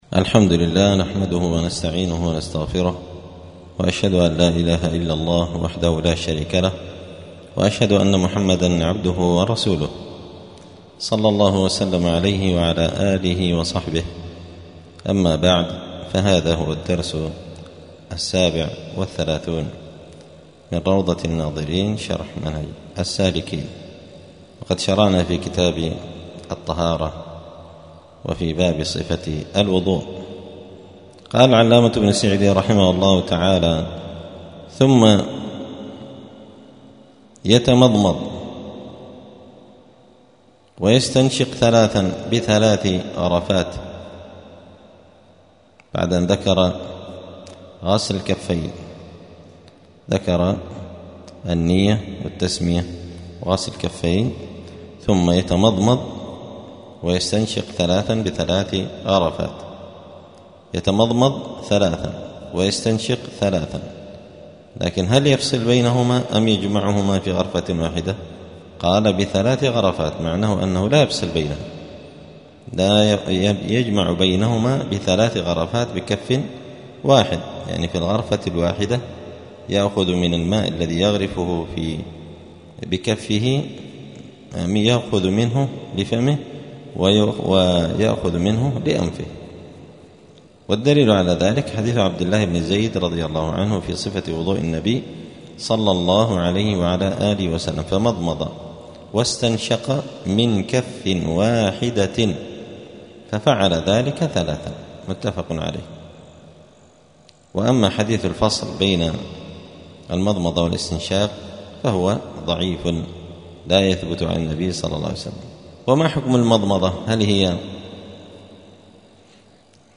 *الدرس السابع والثلاثون (37) {كتاب الطهارة باب صفة الوضوء المضمضة والاستنشاق}*